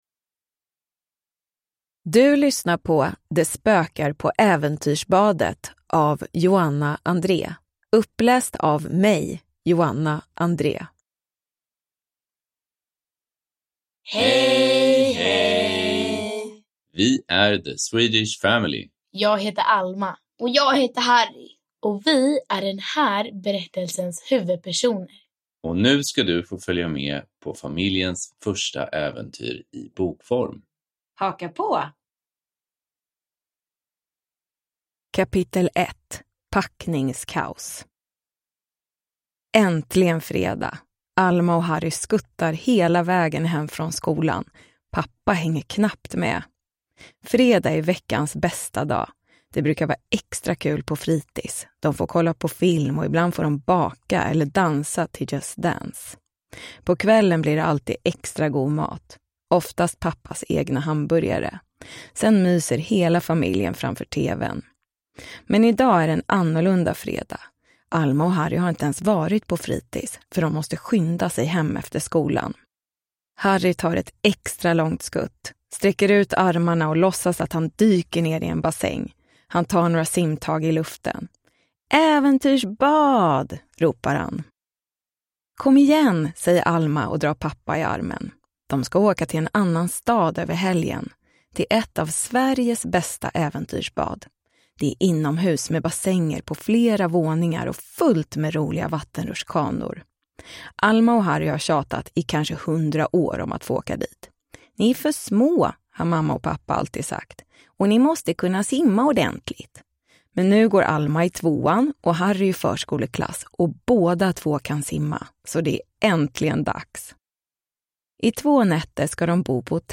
Det spökar på äventyrsbadet – Ljudbok